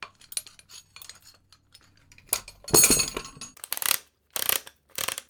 action_repair_0.ogg